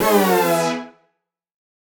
Index of /musicradar/future-rave-samples/Poly Chord Hits/Ramp Down